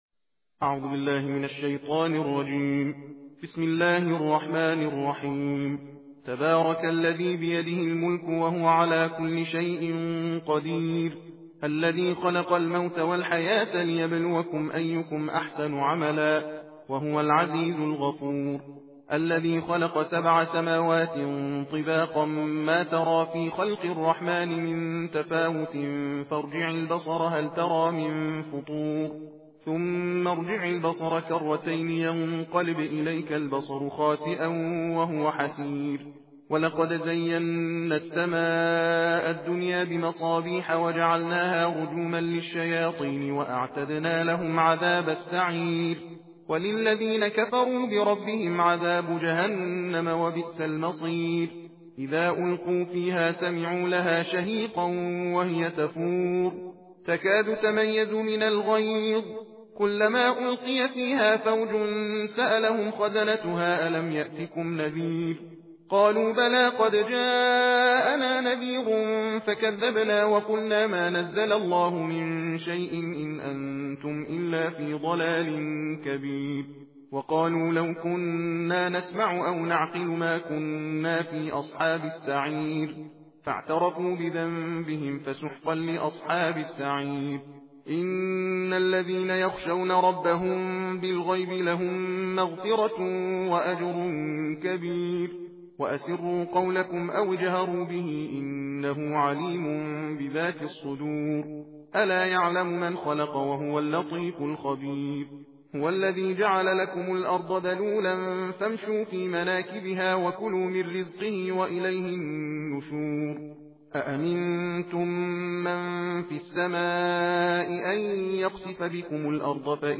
صوت/ تندخوانی جزء بیست و نهم قرآن کریم